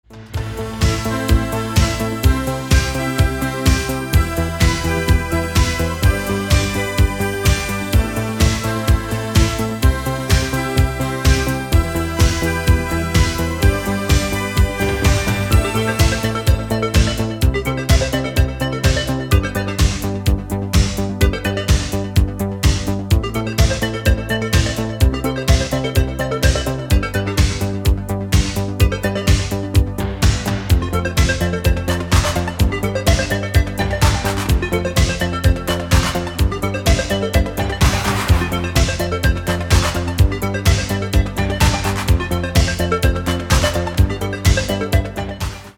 • Качество: 192, Stereo
поп
диско
dance
электронная музыка
спокойные
без слов
дискотека 80-х
итало-диско